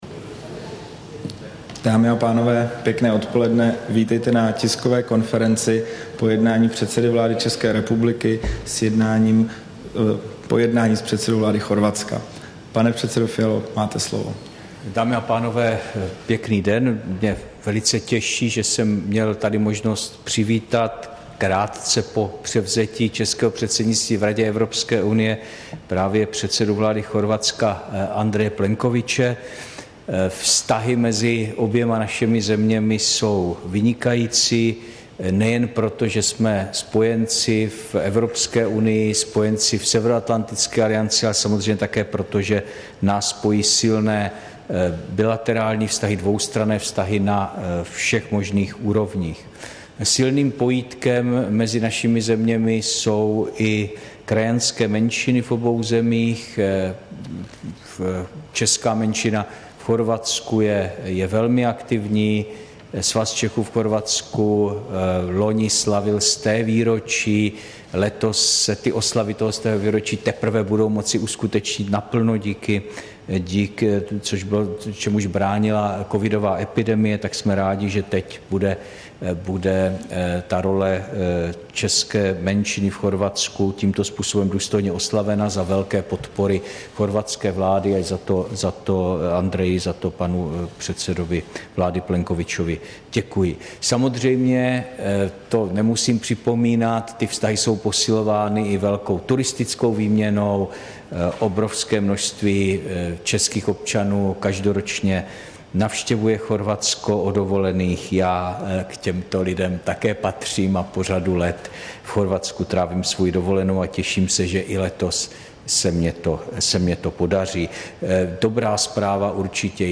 Tisková konference po jednání s chorvatským premiérem Andrejem Plenkovićem, 7. července 2022